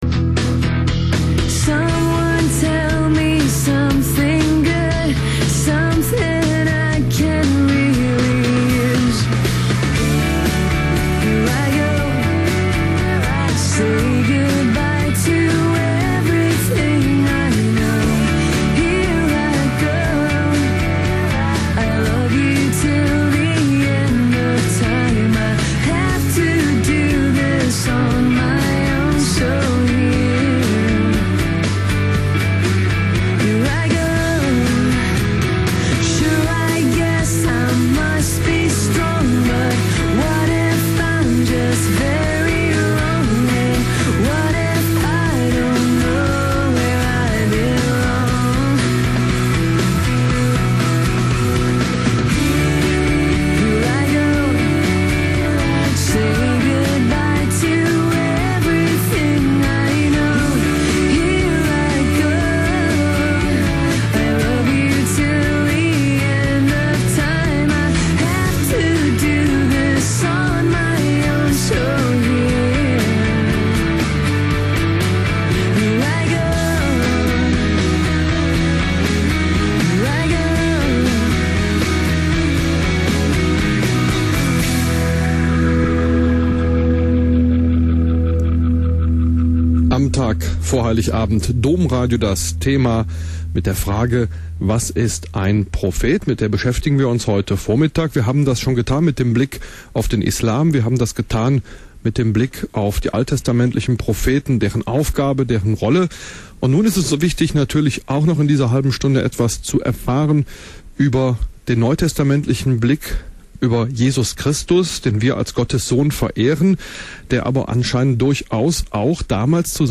Leider ist die Tonqualit�t nicht optimal.